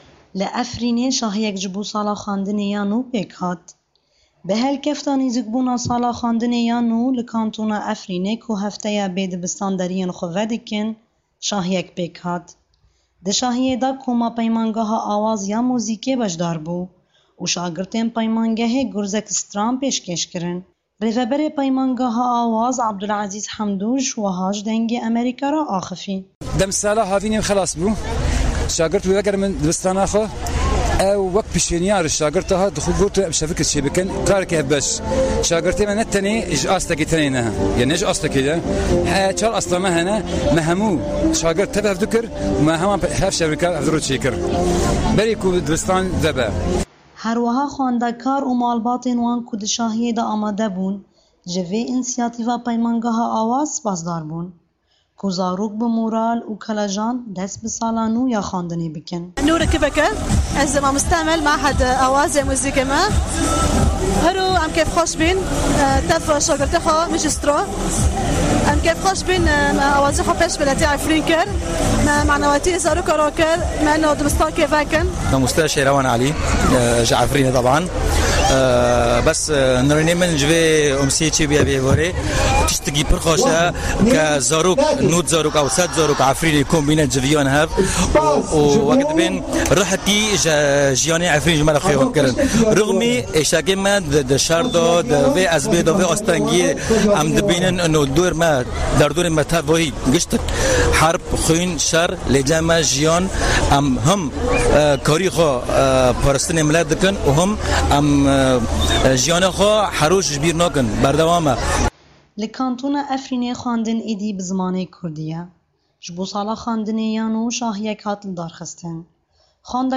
Bi helkefta nêzîkbûna sala xwendinê ya nû li Kantona Efrînê, ku hefteya were dibistan derîyên xwe vedikin, şahîyek li Qada Azadî ya bajêr pêk hat.
Di şahîyê de koma Peymangeha Awaz ya muzîkê beşdar bû û şagirtên peymangehê gurzek stran pêşkêş kirin.